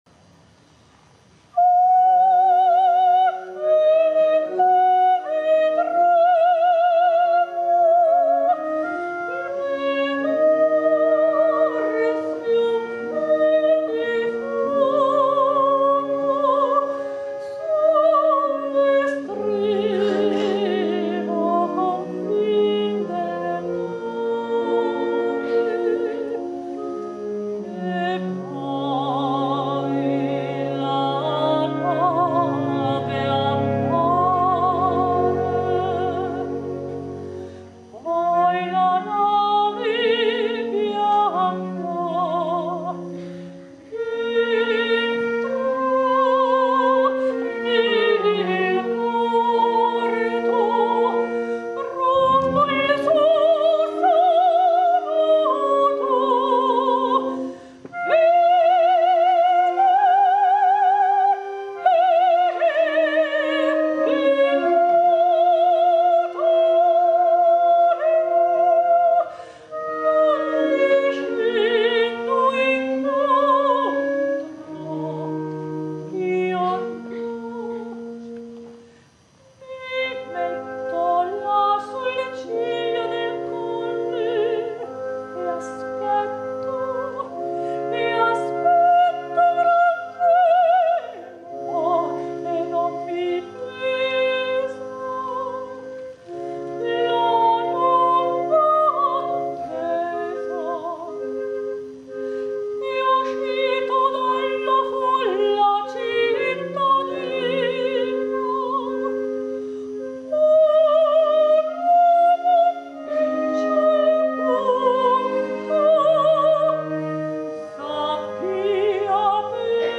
soprano solista
registrato dal vivo: Teatro Vittoria